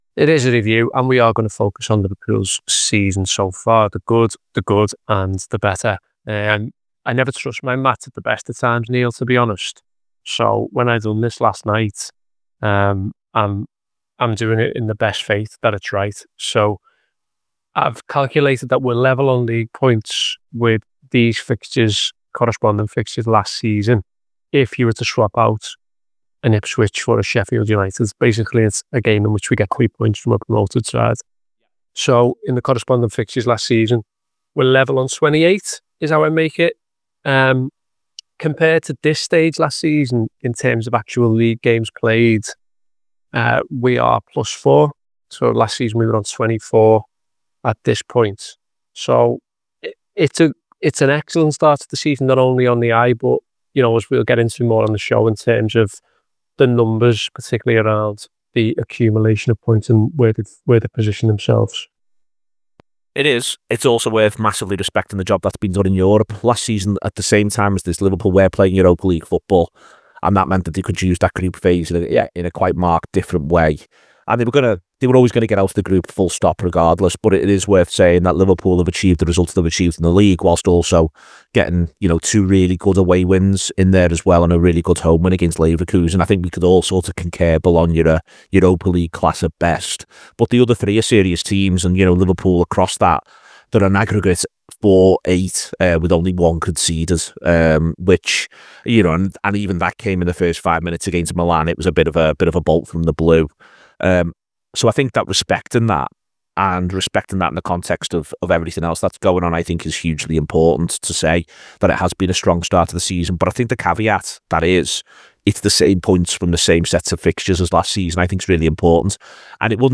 Below is a clip from the show- subscribe for more review chat around Liverpool’s season so far…